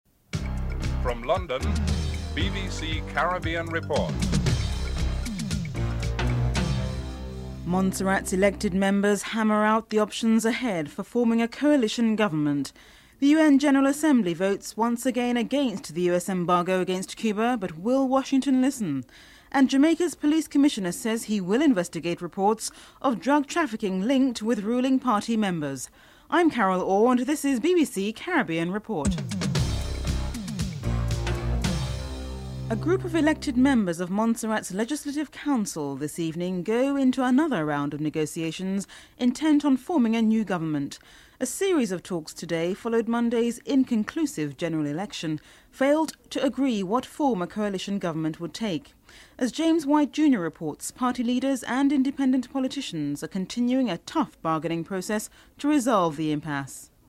Police Commissioner Francis Forbes is interviewed (10:44-11:58)
Opposition Leader Said Musa is interviewed (11:59-15:24)